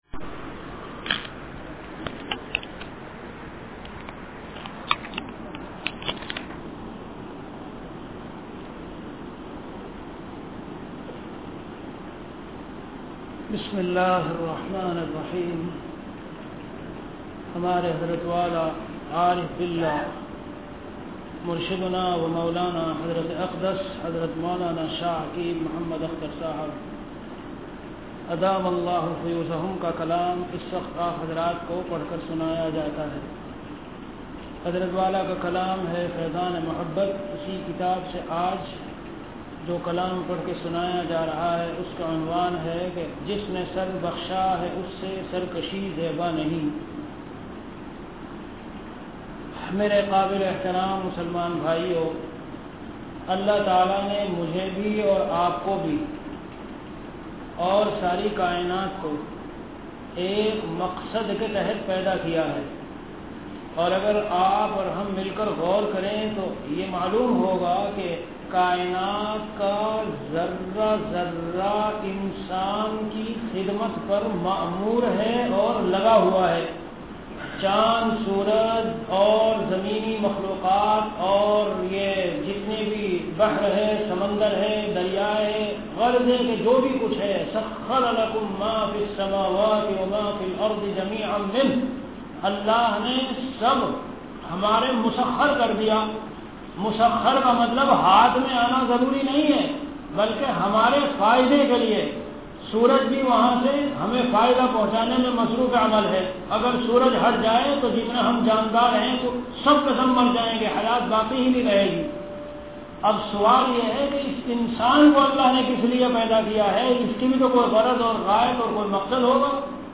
Delivered at Khanqah Imdadia Ashrafia.
Bayanat · Khanqah Imdadia Ashrafia